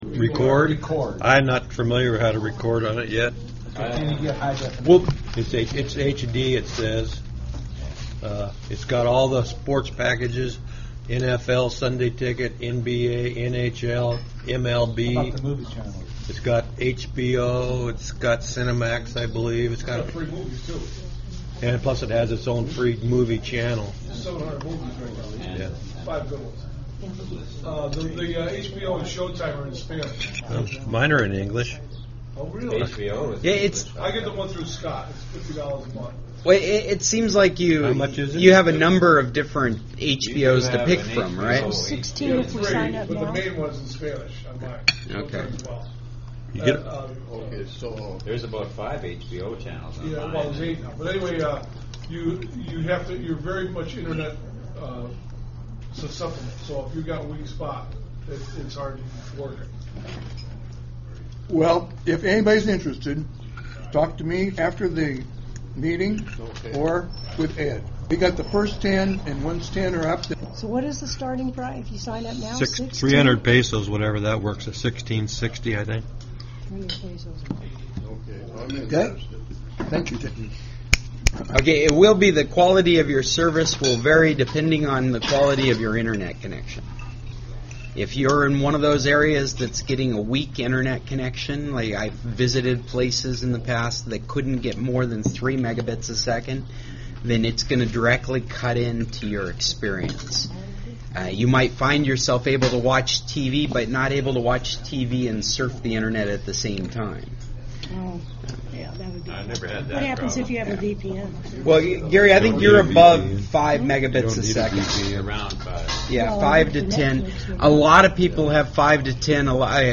We had a huge group. Our normal large table + three more.